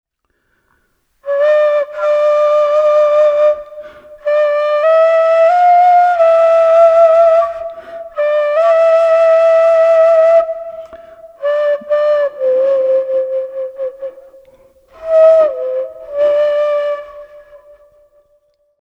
Kōauau
This instrument is a member of the Hine Raukatauri whānau. It has three fingers holes and is blown from one end.